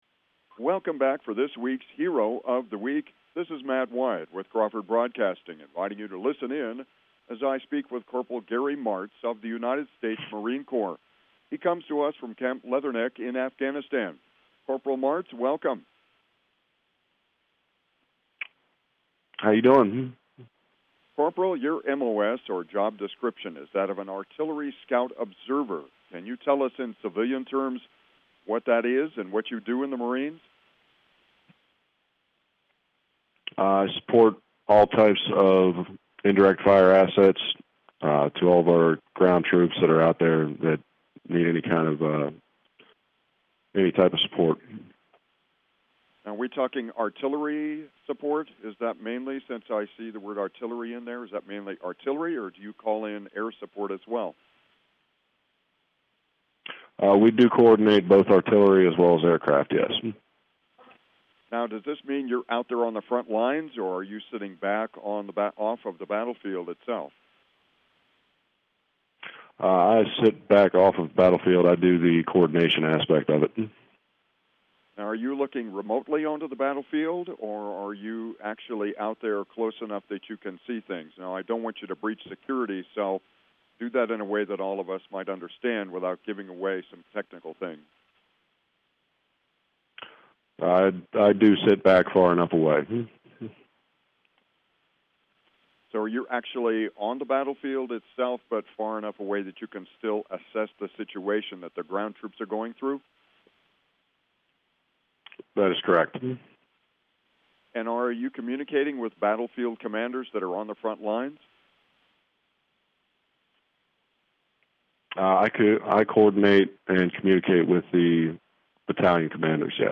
talks to a Crawford Broadcasting reporter about providing assets and support to Marine ground troops while in Afghanistan.